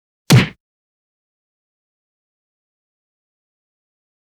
赤手空拳击中肉体8-YS070524.wav
通用动作/01人物/03武术动作类/空拳打斗/赤手空拳击中肉体8-YS070524.wav
• 声道 立體聲 (2ch)